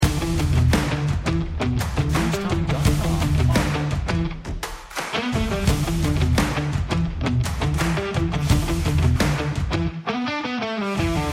Stomp Rock